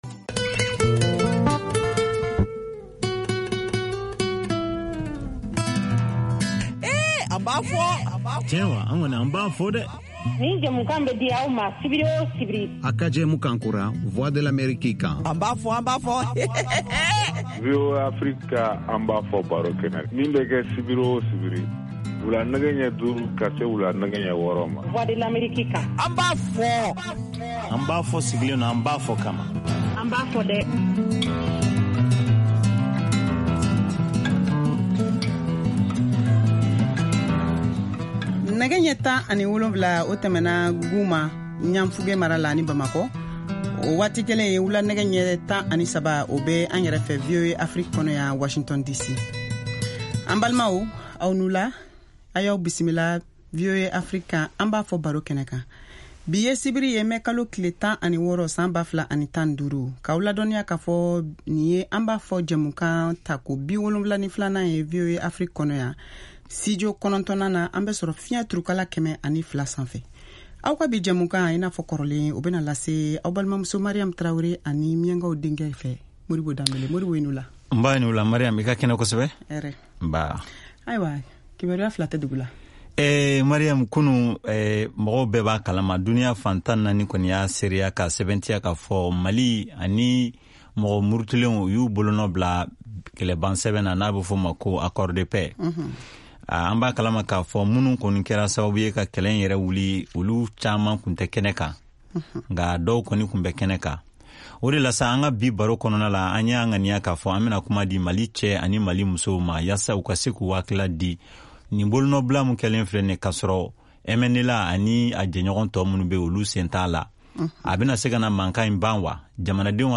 émission interactive en Bambara diffusée en direct depuis Washington, DC